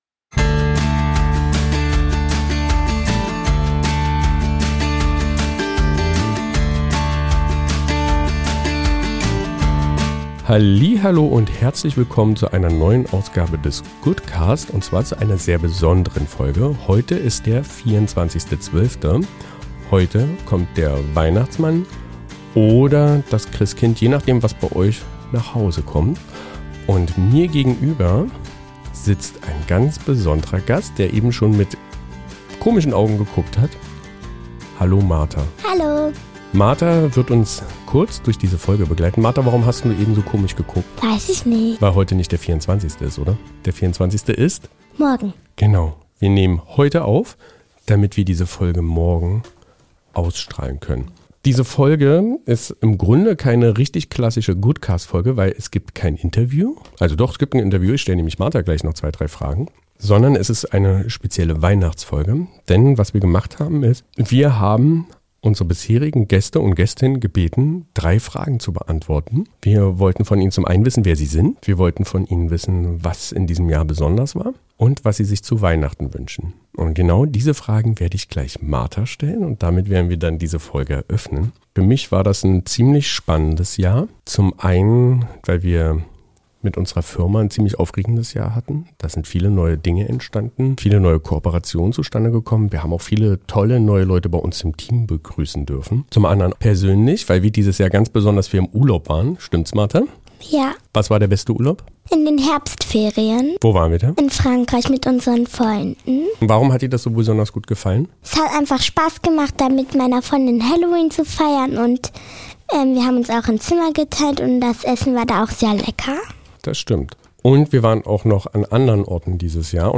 In dieser besonderen Folge des Goodcast hören wir viele Stimmen. Ehrlich, berührend, hoffnungsvoll. Gäste teilen besondere Momente aus ihrem Jahr 2025: kleine Augenblicke, große Wendepunkte, leise Erkenntnisse. Und sie erzählen, was sie sich zu Weihnachten wünschen.
Diese Folge ist ein akustisches Mosaik aus Erinnerungen, Wünschen und Zuversicht.